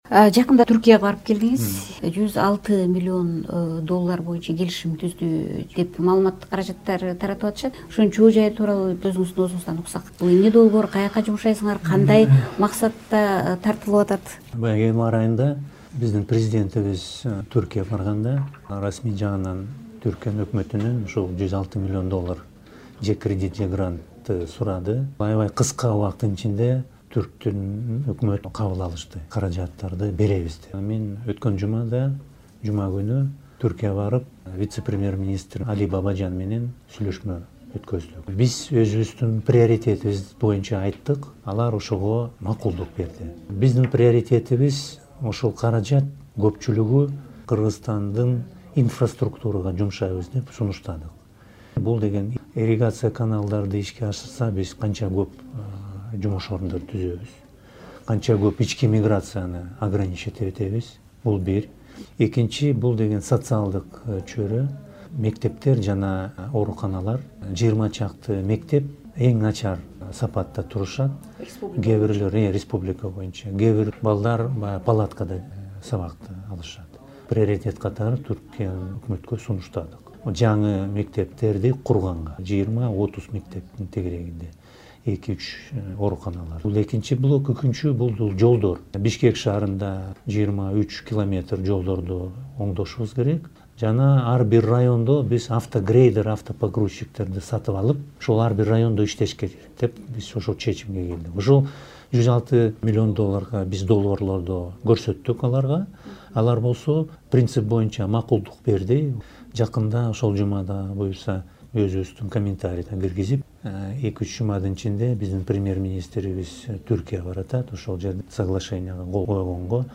Жоомарт Оторбаев менен маек